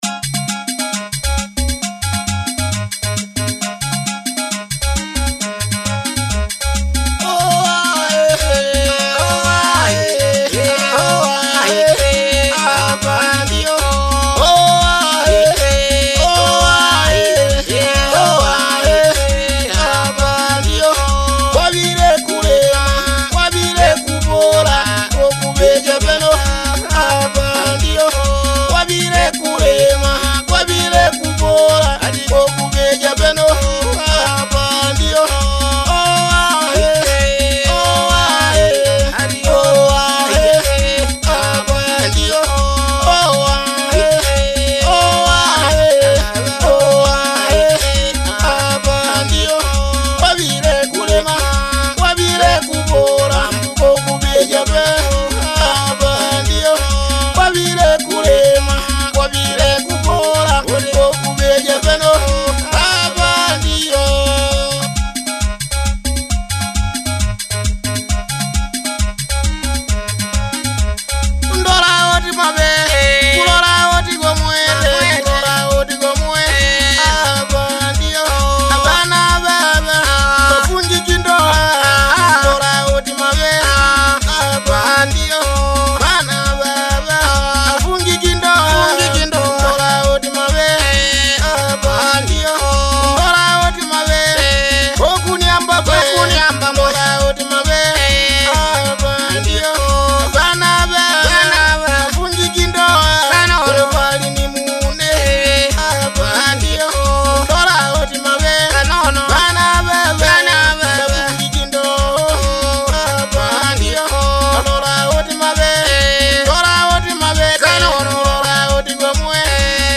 Nyimbo za Kimatengo